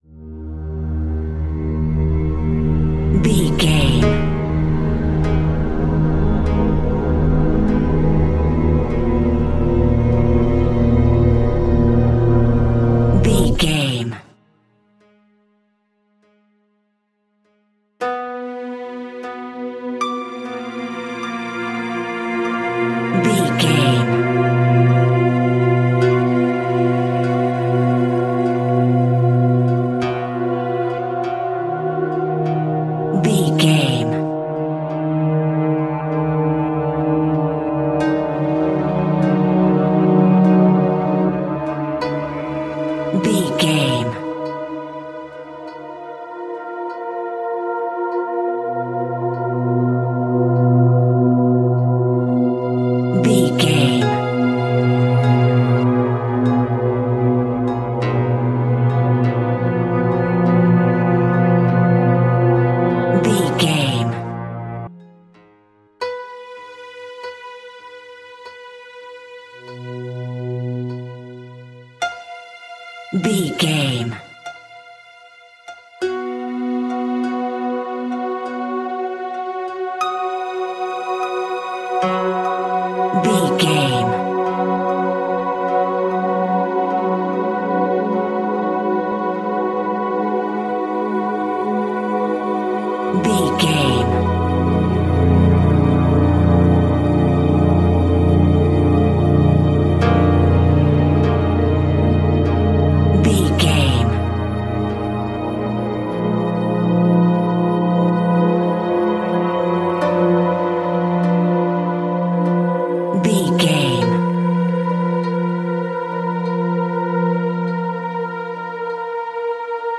Atonal
Slow
scary
tension
ominous
dark
suspense
eerie
strings
synthesiser
horror
keyboards
pads